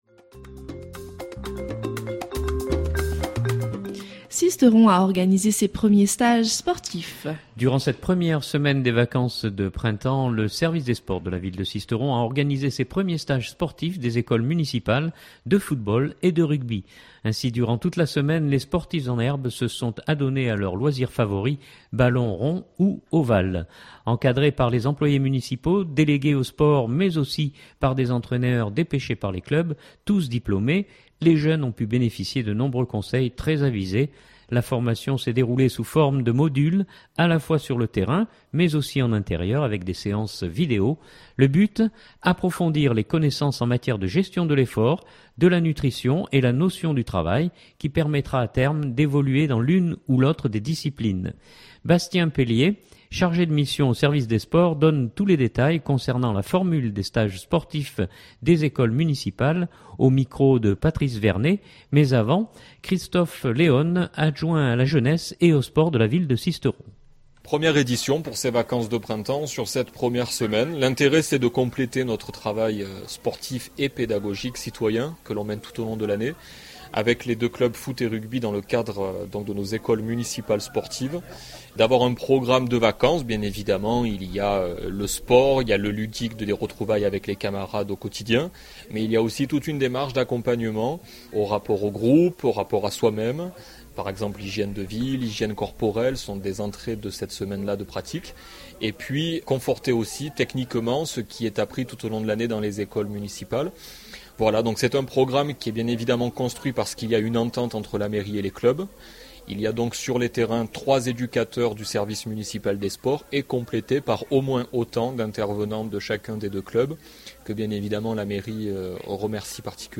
Mais avant, Christophe Léone, adjoint à la jeunesse et aux sports.